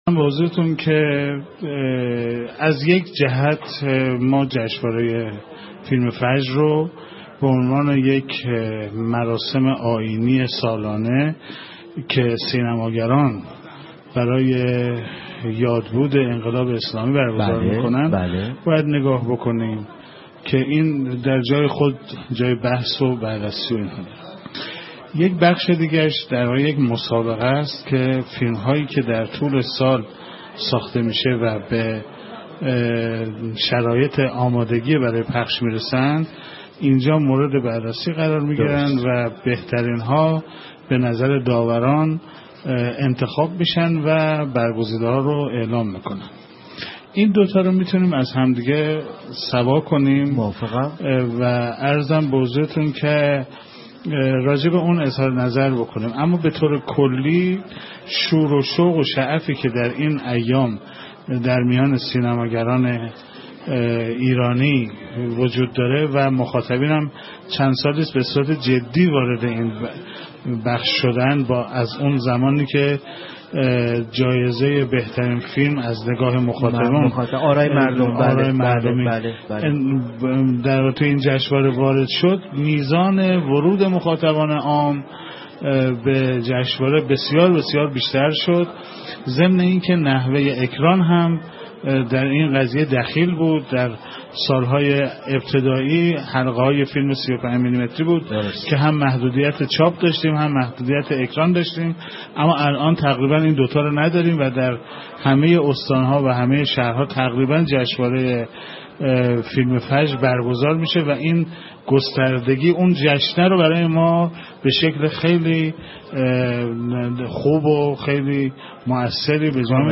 گفتگو با رادیو فرهنگ در باره جشنواره فیلم فجر 43